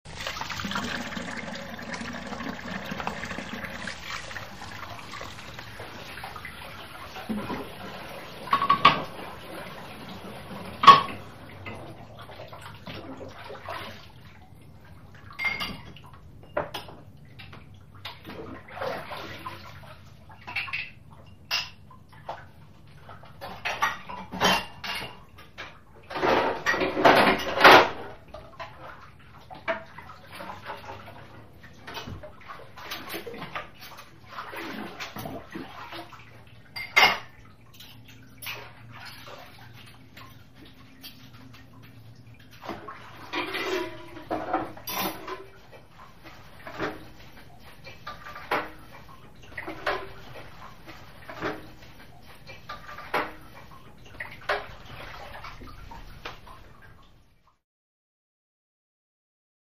Dish Clinks | Sneak On The Lot
Water Running With Dish Clank And Water Dunks Washing Dishes